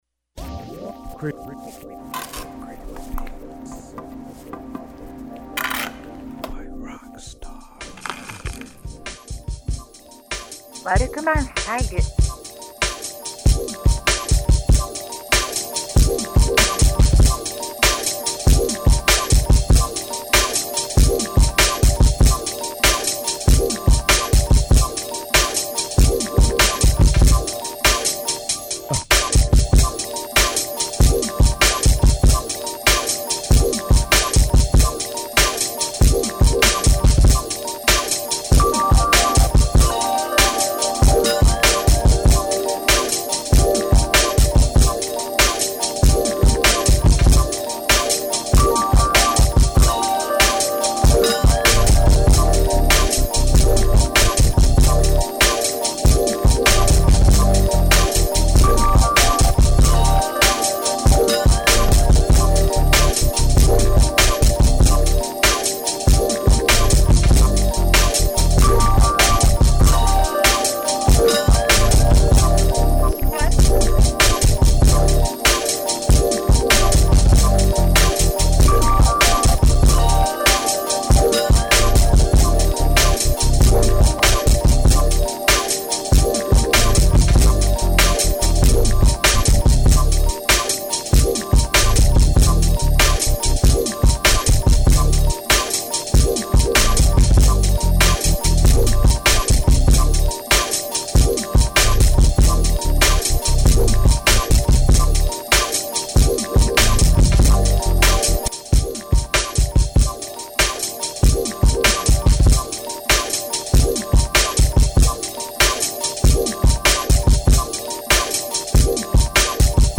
Early Summer 2021 instrumentals set.